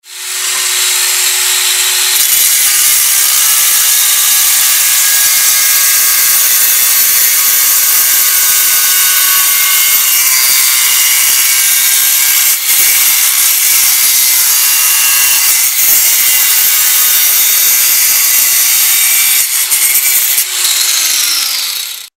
На этой странице собраны реалистичные звуки работающих станков: токарных, фрезерных, шлифовальных и других.
Звук болгарки режущей бетон